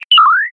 open-safe.ogg.mp3